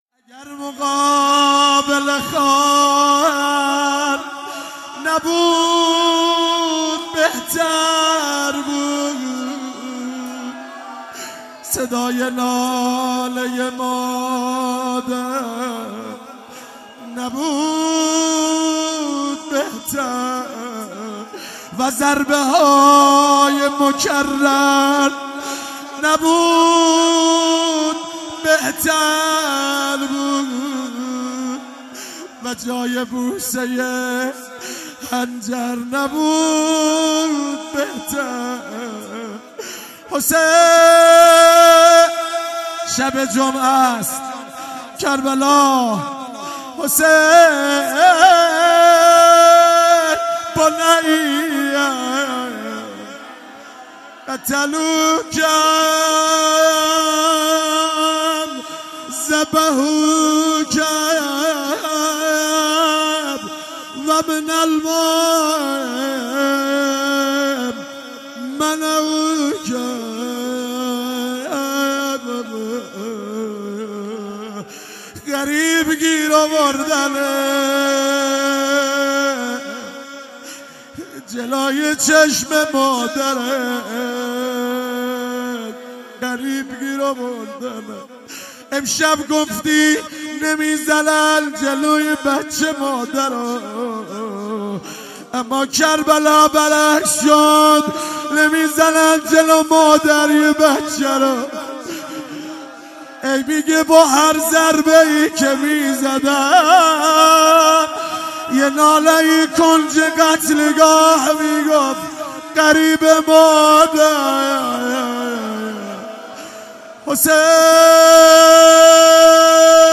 شب پنجم فاطمیه 95_روضه پایانی